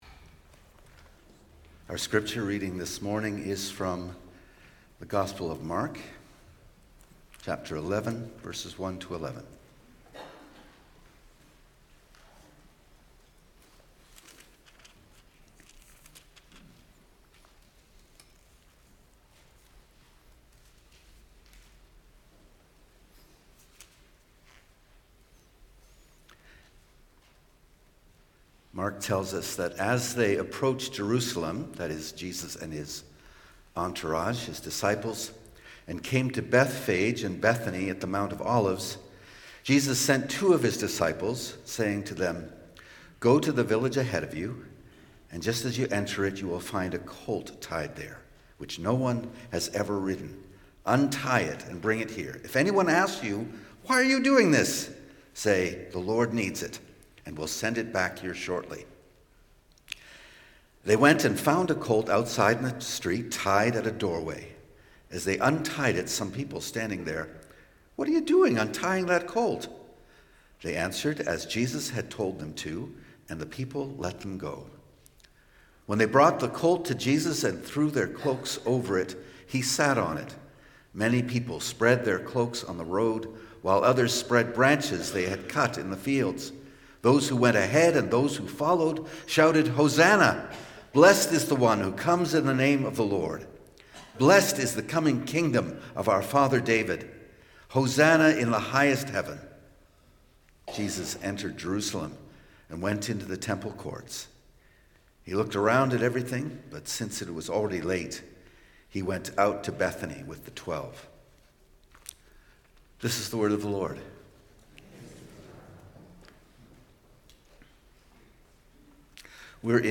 Sermons | Community Christian Reformed Church
Palm Sunday